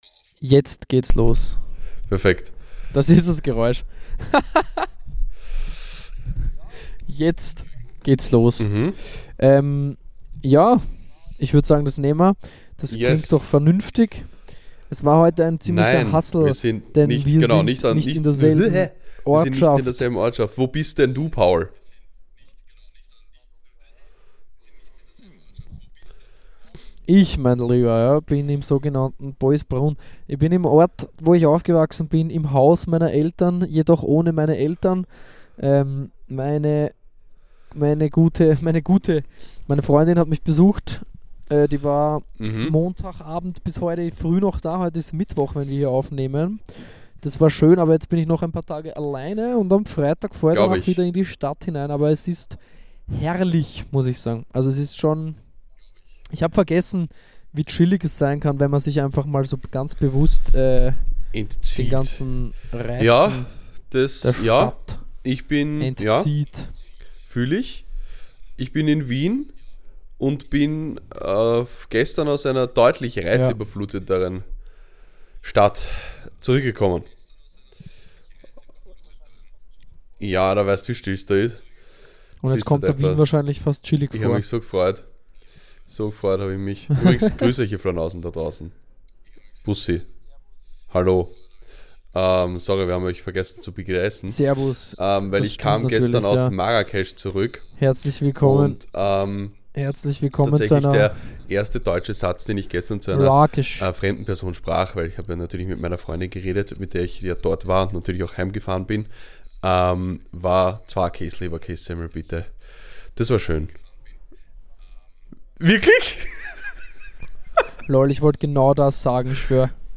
Diesmal remote und mit anfänglichen Schwierigkeiten - bitte entschuldigt etwaige qualitätslücken.